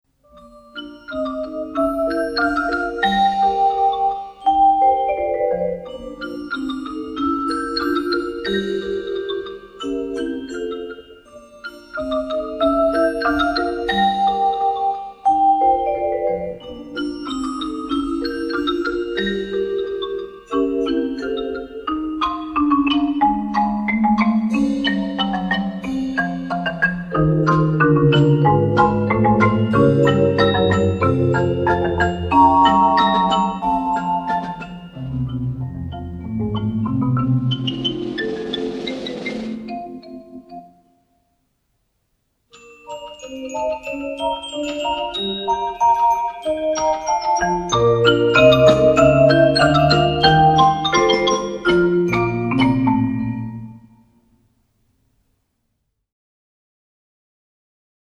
оркестровая (ударная группа) версия